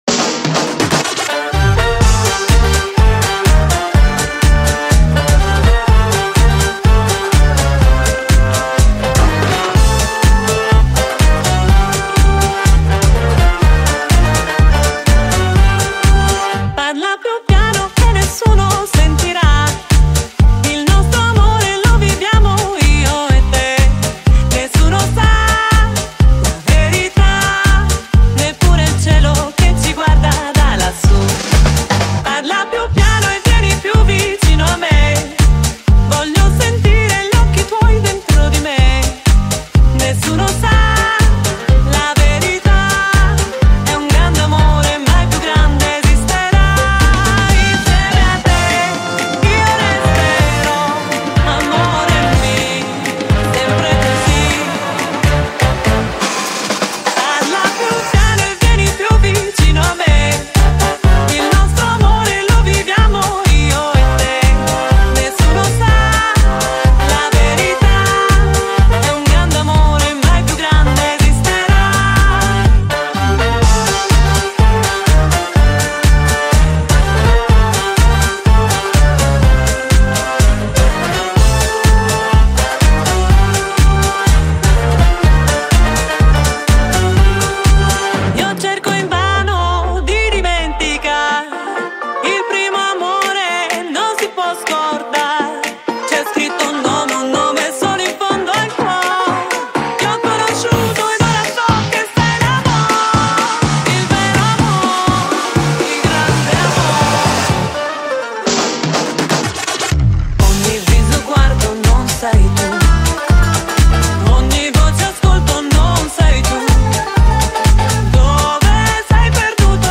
Electro Swing Mix